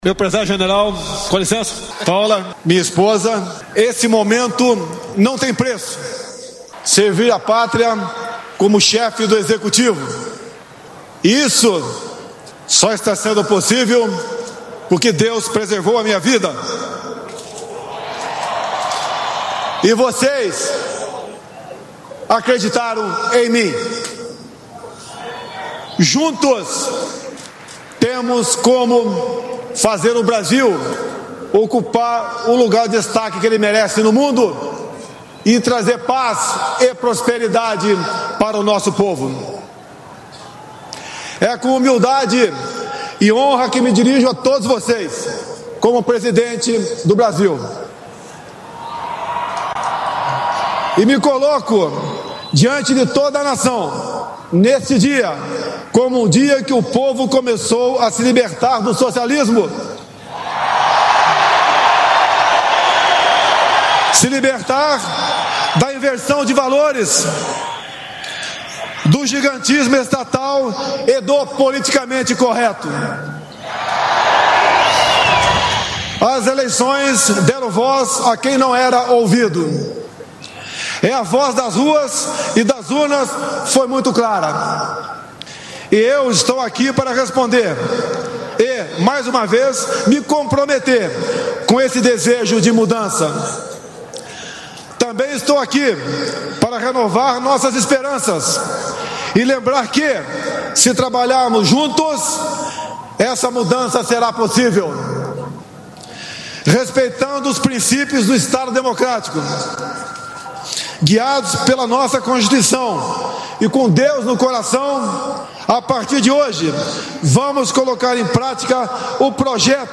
Pronunciamento do presidente Jair Messias Bolsonaro
Posse Presidencial